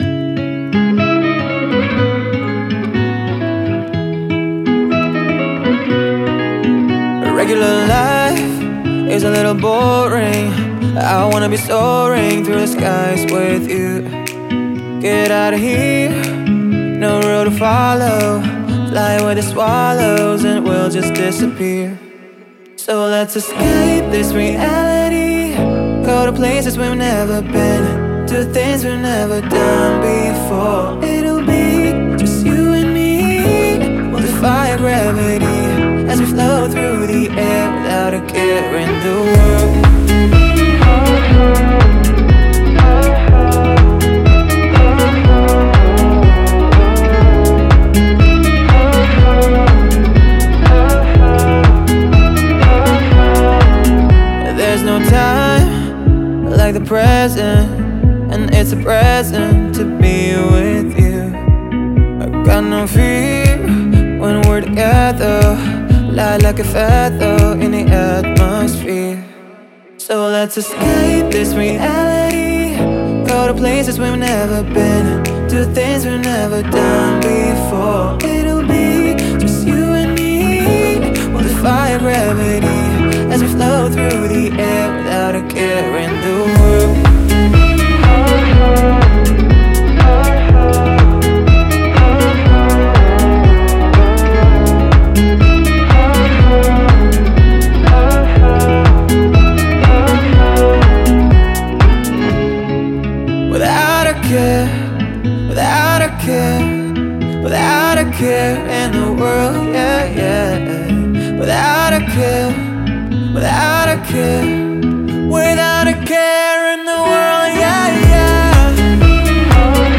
это яркая и мелодичная композиция в жанре поп